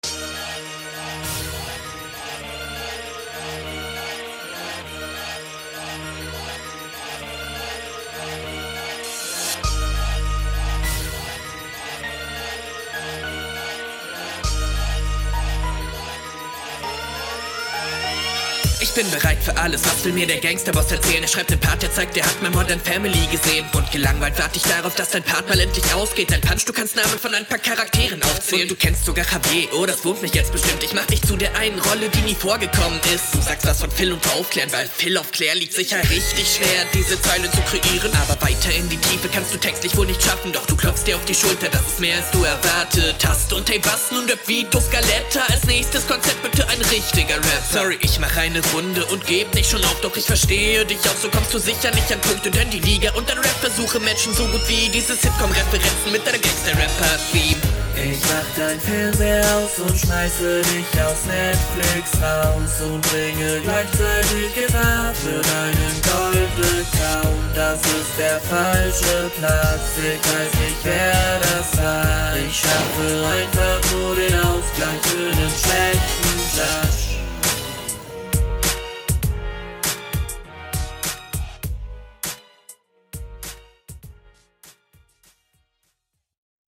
autotune 😍😍😍😍